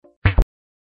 Вы можете слушать онлайн и скачать бесплатно в mp3 рингтоны входящих звонков, мелодии смс-уведомлений, системные звуки и другие аудиофайлы.